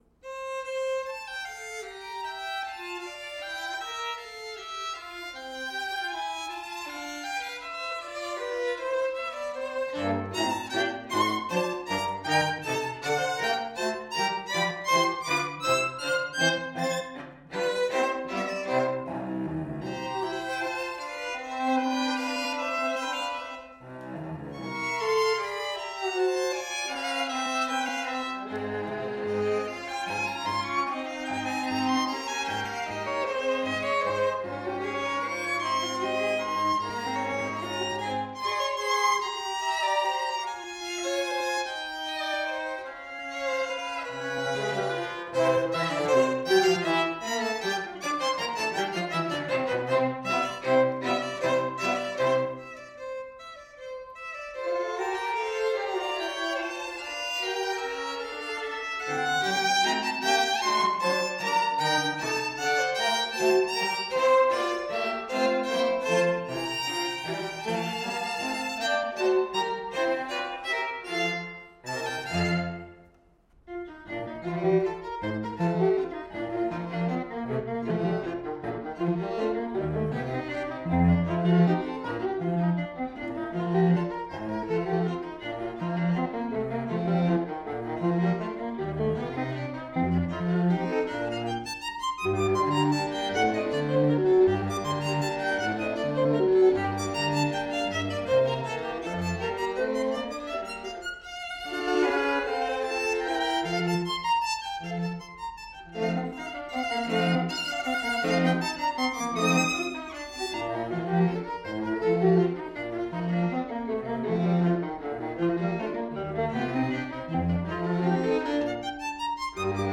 march-like Menuetto, allegretto